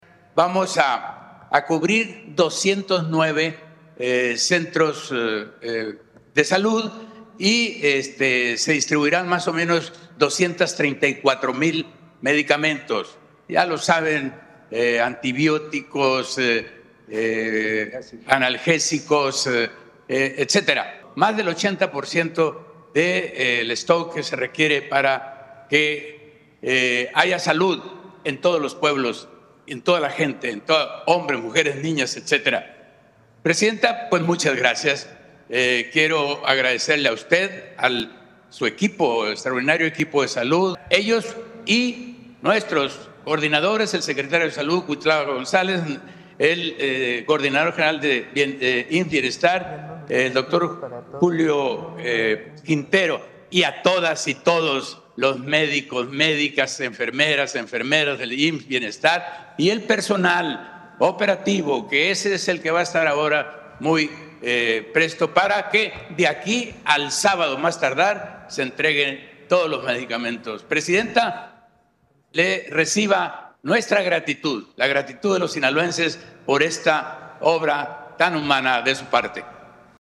Precisamente correspondió al gobernador Rubén Rocha Moya ser el primero en participar en este enlace, una vez que la presidenta Claudia Sheinbaum le dio a la palabra, y por ello el mandatario estatal le agradeció este vital programa que consiste en llevar los medicamentos a todos los rincones de Sinaloa y de México.
CITA-AUDIO-GOBERNADOR-RRM-BANDERAZO-DE-INICIO-PROGRAMA-RUTAS-DE-LA-SALUD-1.mp3